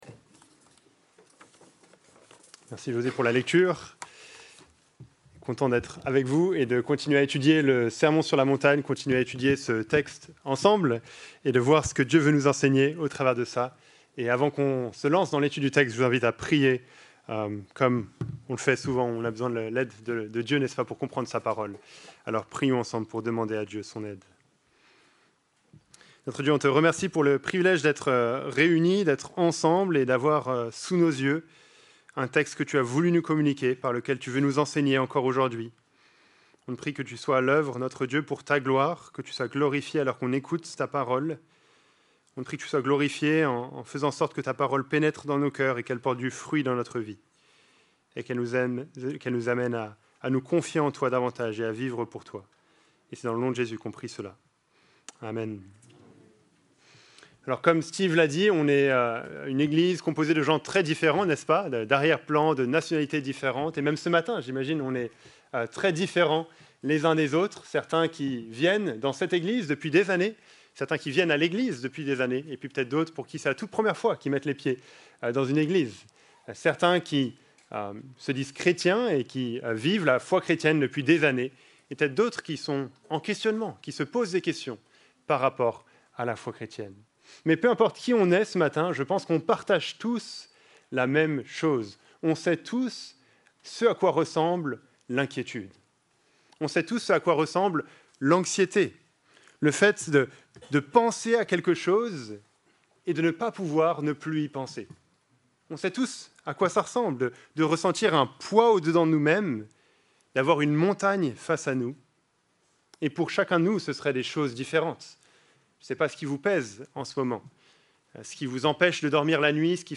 Plan de la prédication #1 – Ayez les bonnes priorités (v.19-24) Le bon trésor (v.19-21) Le bon œil (v.22-23) Le bon maître (v.24) Ce qui...